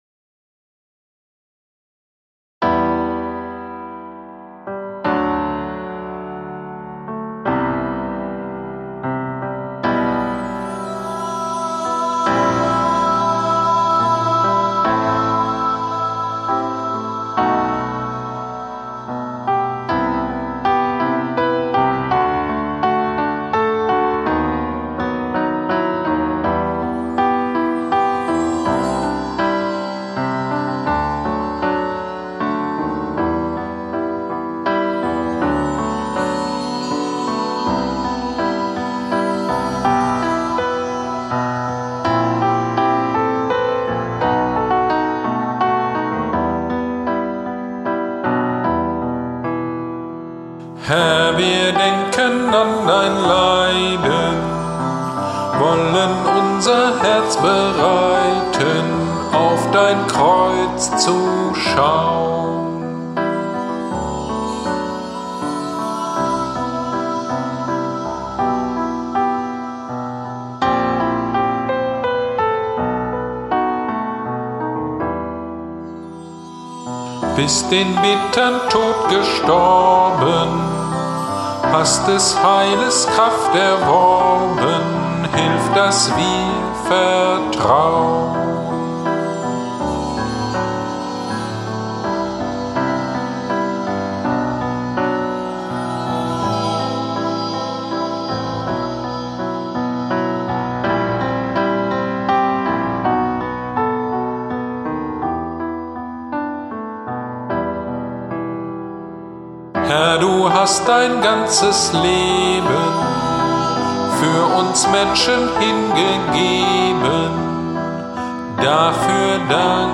Predigt am Karfreitag 2021 zu Matthäus 27,35-42 - Kirchgemeinde Pölzig